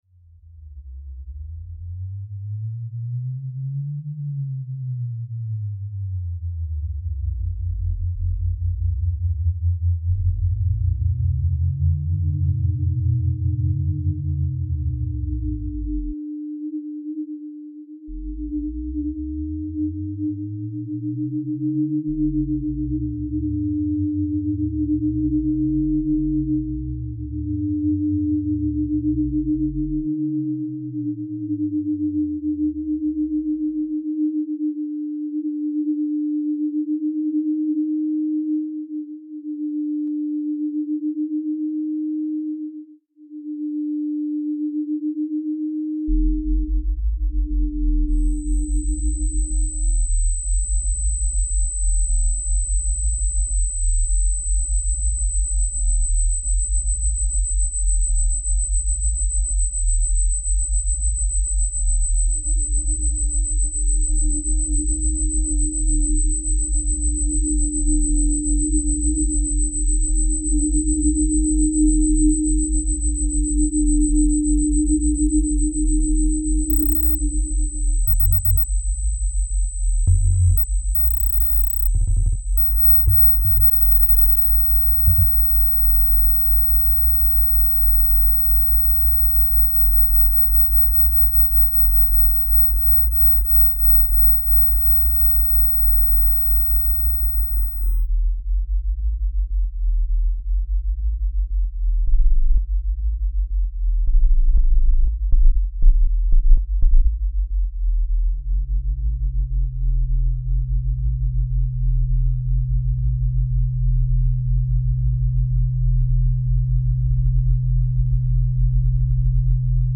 sound installation